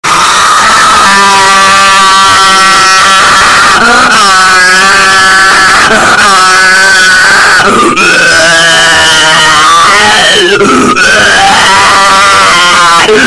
IShowSpeed Scream
Voicy_ishowspeed-screaming.mp3